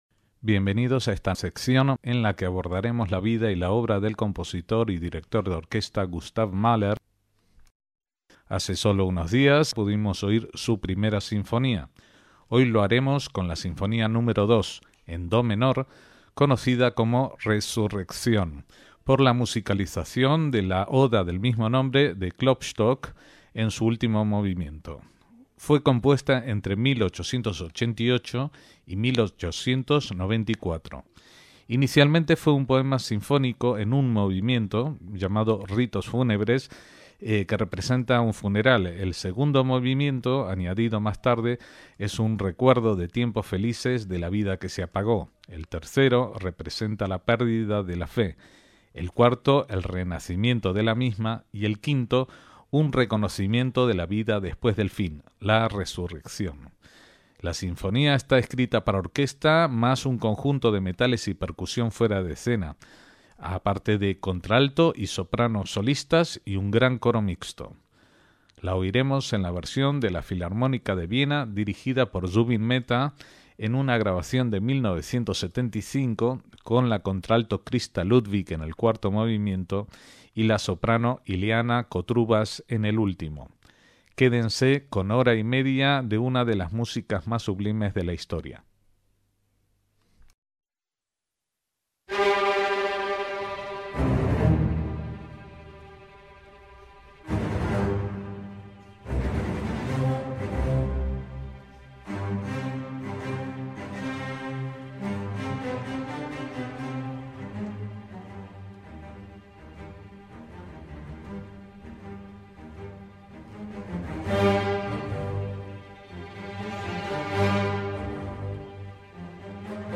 en do menor
sinfonía coral